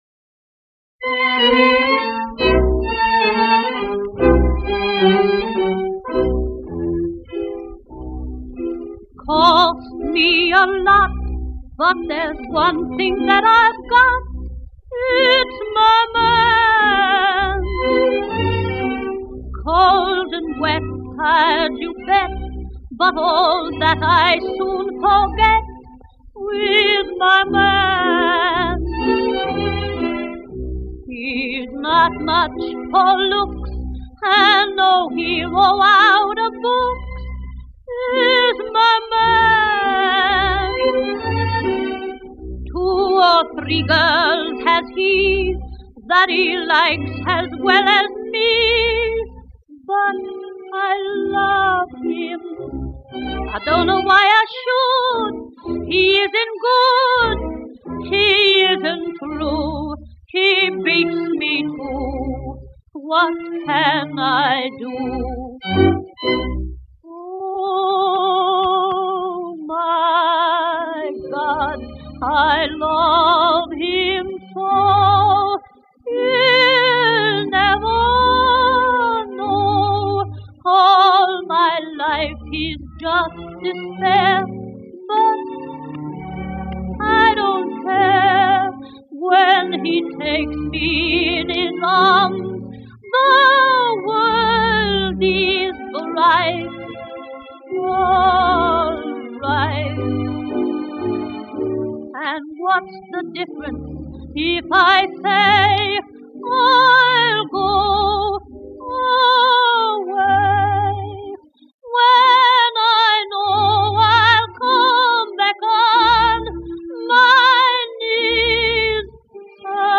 イラストレイテッド・ソングのモデル、歌手、舞台女優